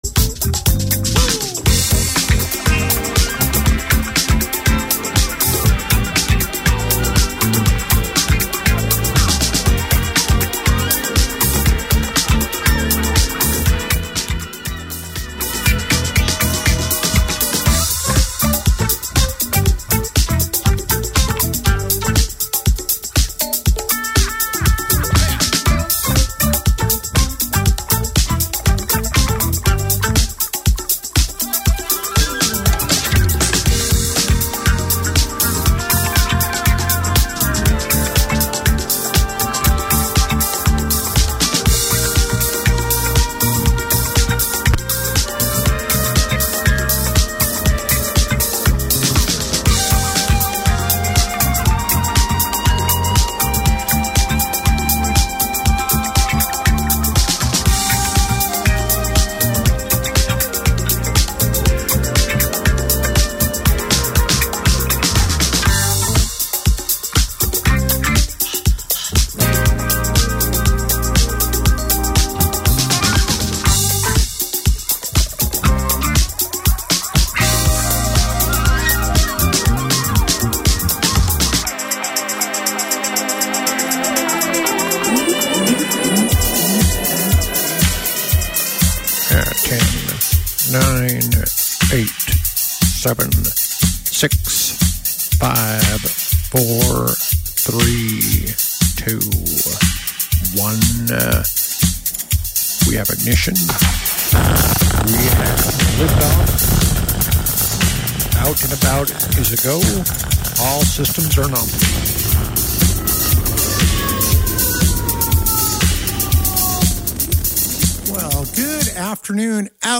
We had a far-ranging conversation that I really enjoyed and I think you will too.